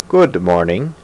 Good Morning Sound Effect
good-morning.mp3